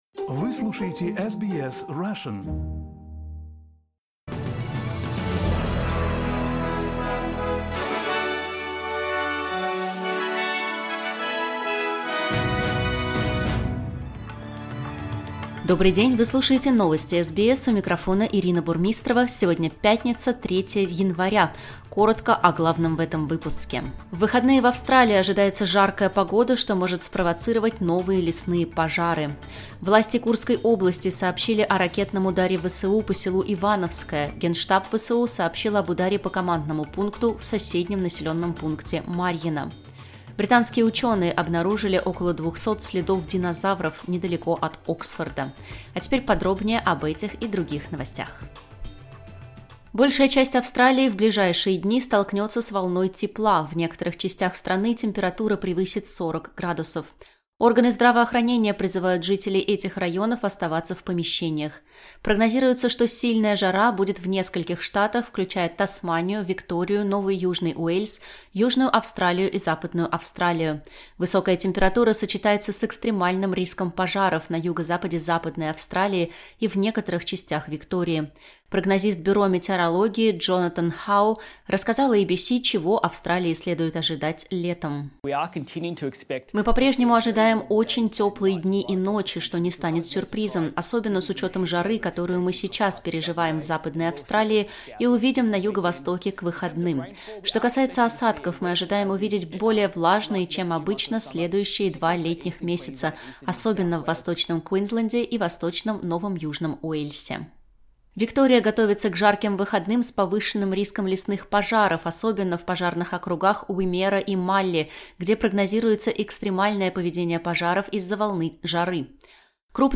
Новости SBS на русском языке — 3.01.2025